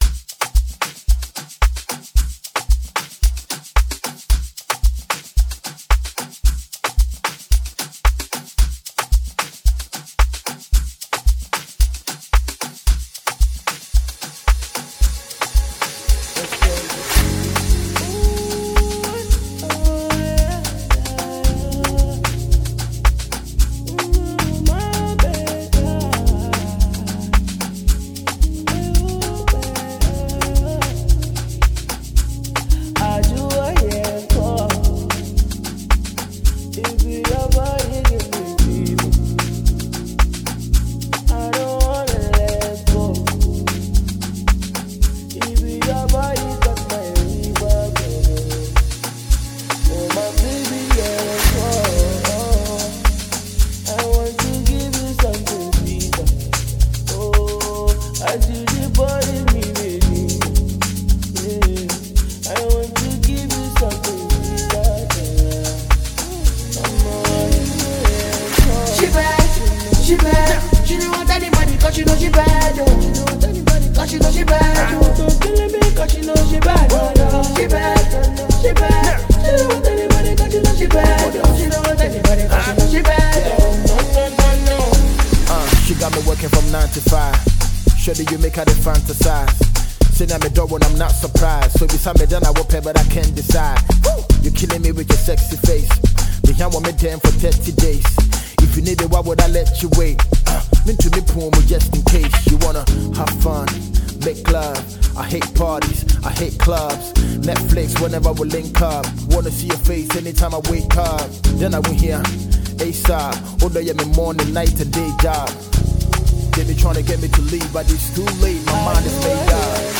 Ghanaian veteran rapper